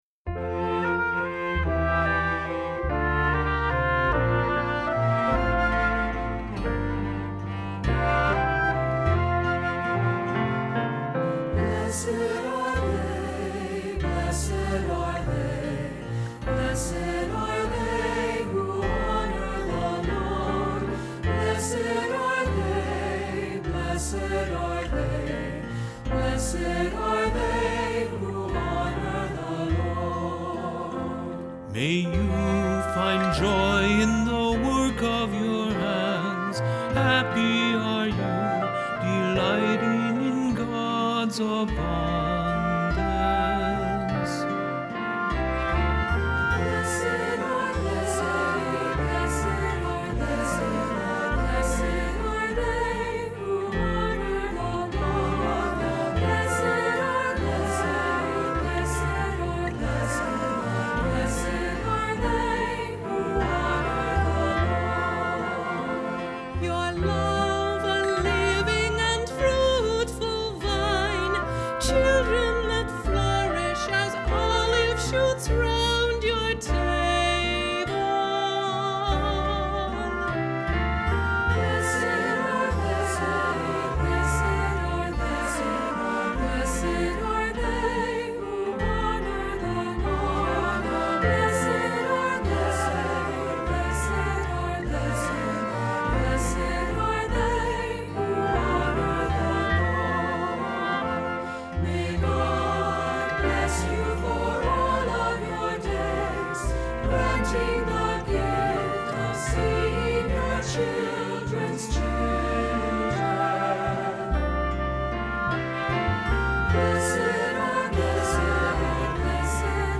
Voicing: SAB, Piano and Guitar